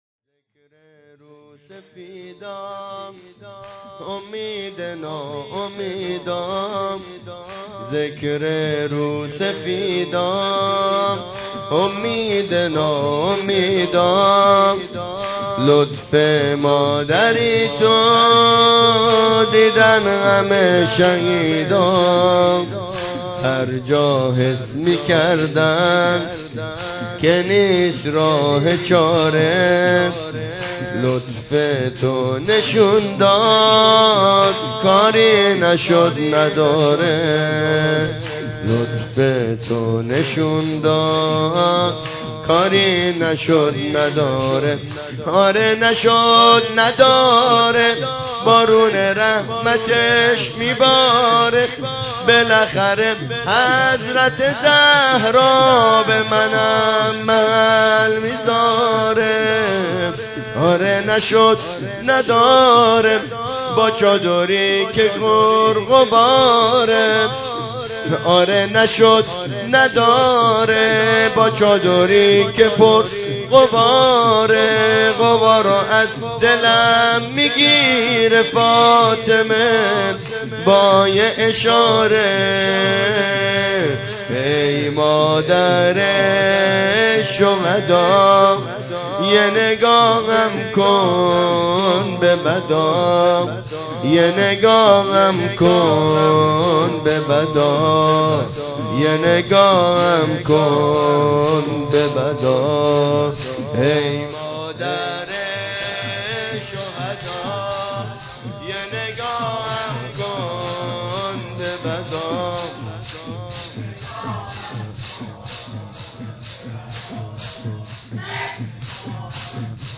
شور پایانی شور شهدایی